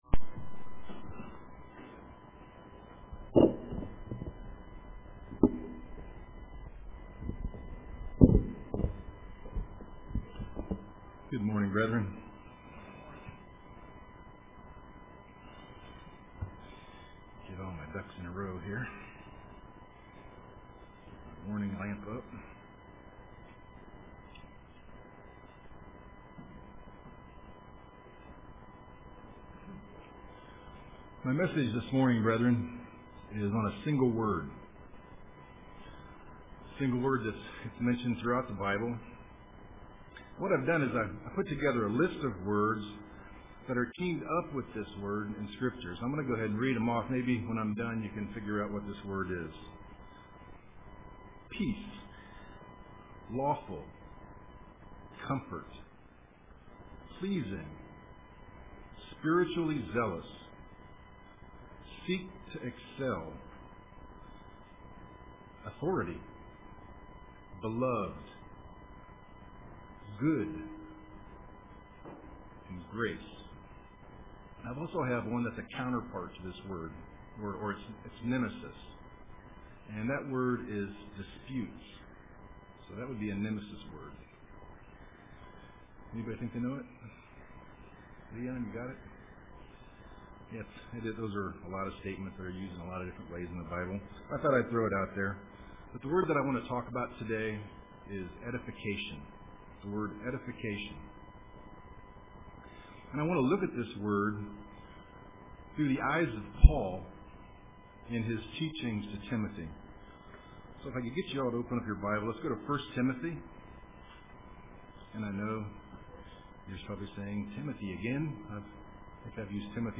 Print Godly Edification UCG Sermon